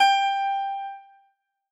Harpsicord